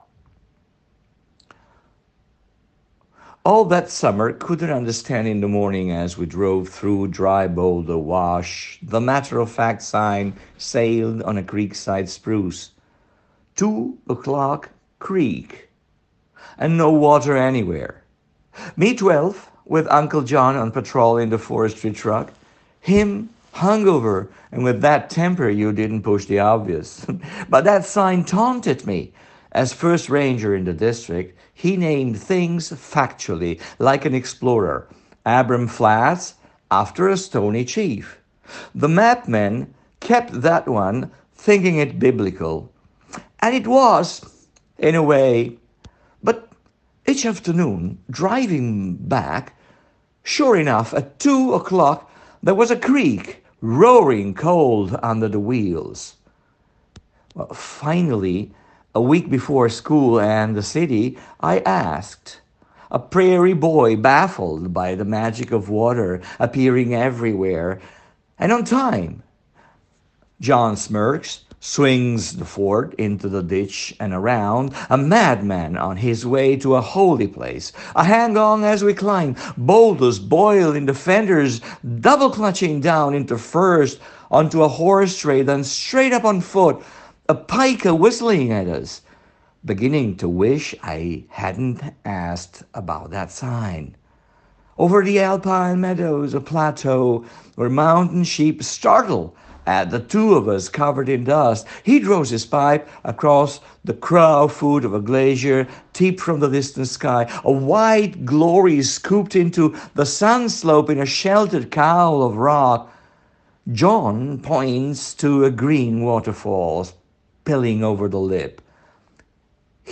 lettura in lingua originale